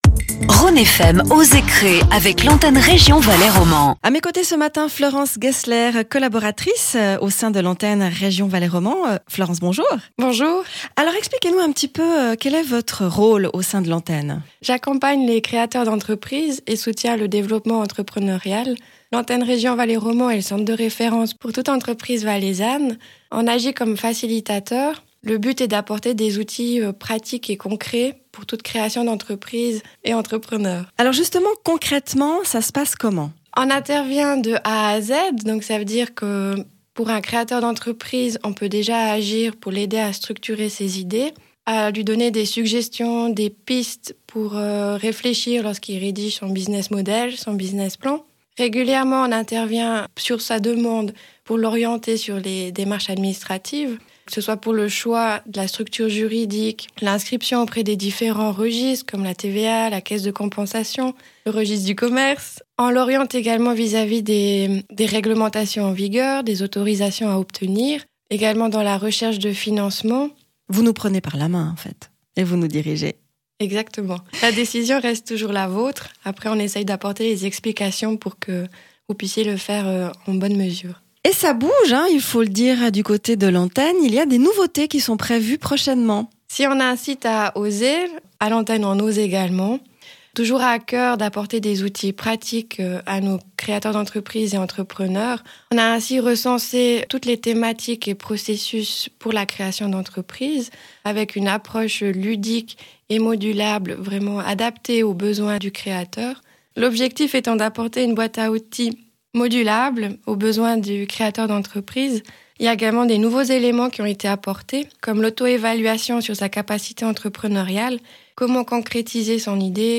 Chaque semaine, découvrez le témoignage d’un entrepreneur qui a créé son entreprise et d’un président de commune. Des interviews de deux minutes consacrées à l’esprit entrepreunarial pour mieux connaître une région et son économie.
Ecoutez ou réécoutez la rubrique « Oser créer » diffusée sur Rhône FM le vendredi à 11h50.